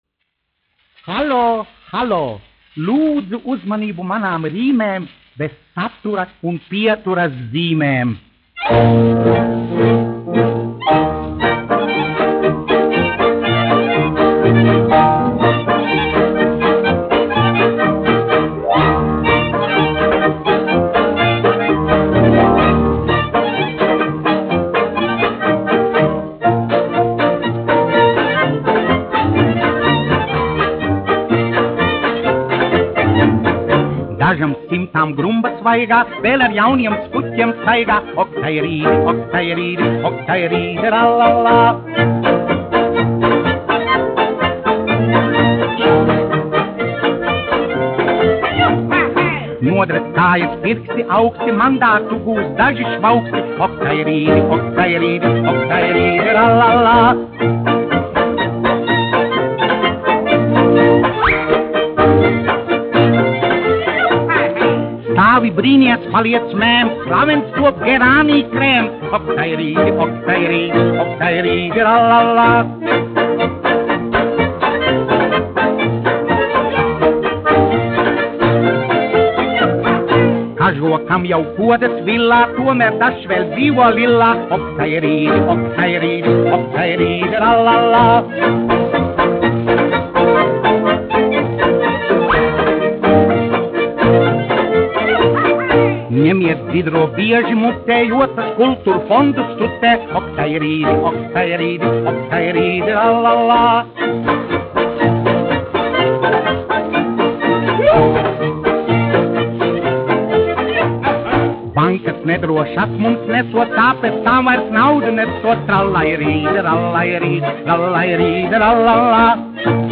1 skpl. : analogs, 78 apgr/min, mono ; 25 cm
Polkas
Humoristiskās dziesmas
Skaņuplate